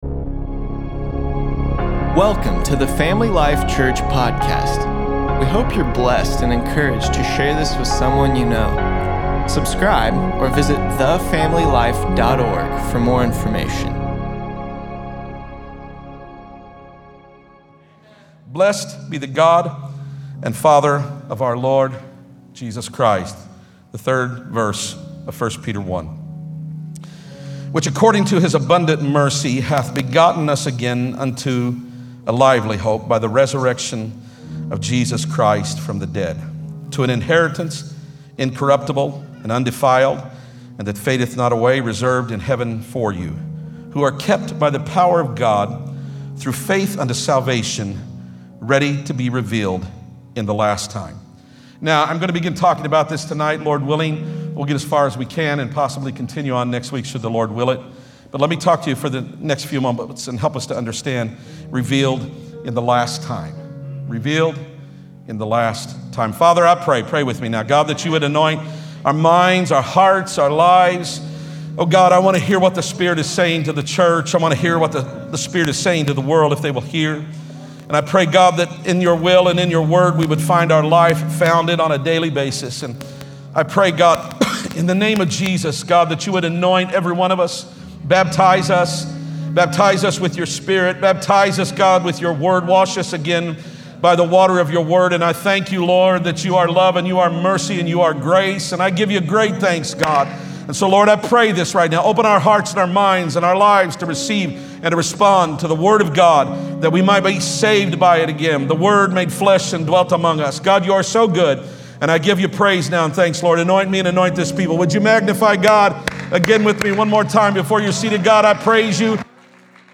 10.7.20_sermon_p.mp3